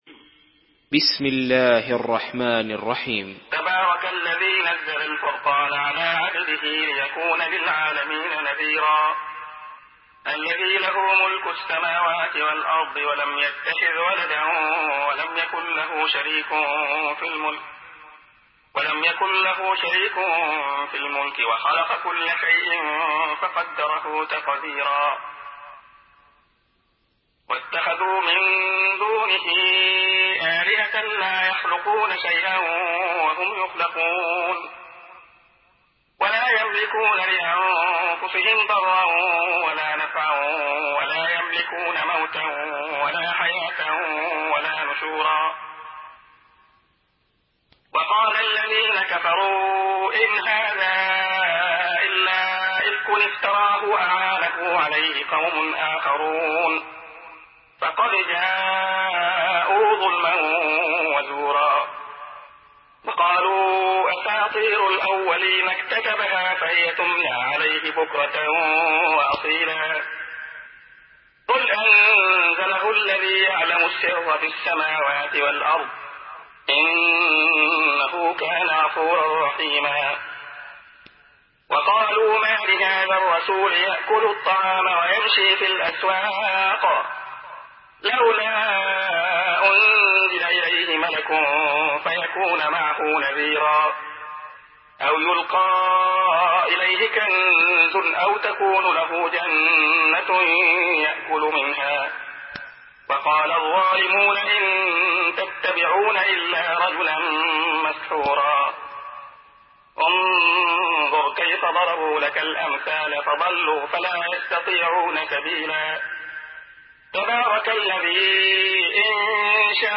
Surah Furkan MP3 by Abdullah Khayyat in Hafs An Asim narration.
Murattal Hafs An Asim